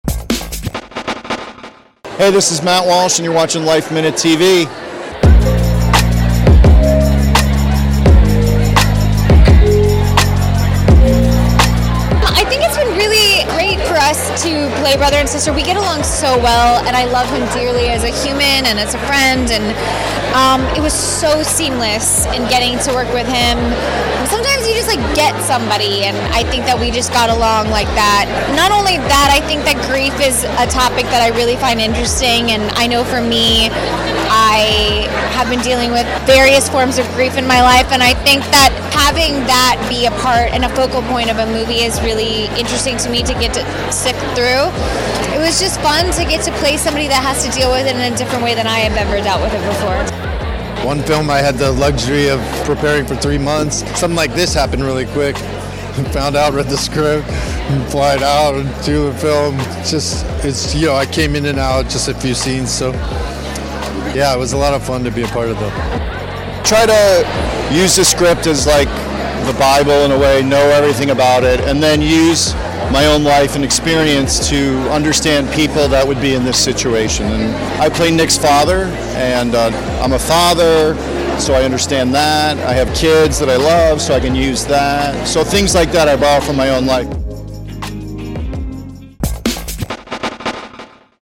Nick Jonas, Brittany Snow, David Arquette, and Matt Walsh at Tribeca Film Festival Premiere of The Good Half
LifeMinute chats with the cast about acting in the emotional film, and they share their own struggles with grief.